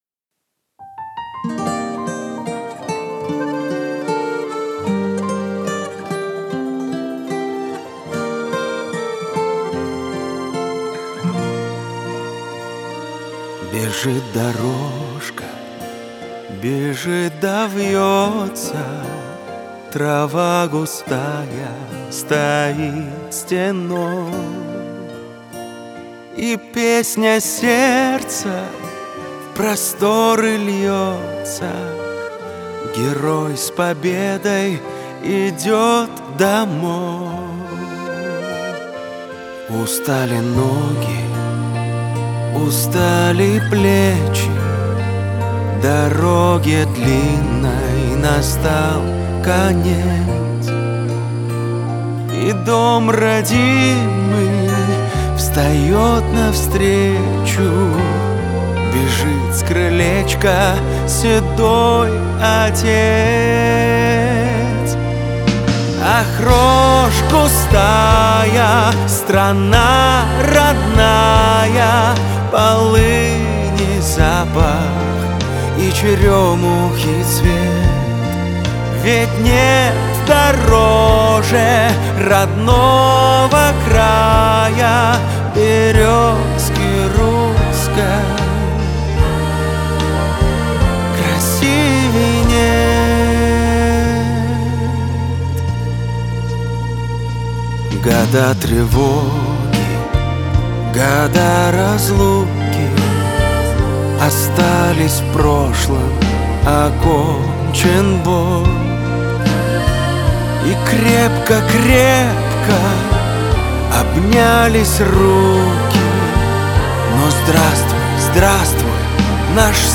аккордеон
гитара
бэк-вокал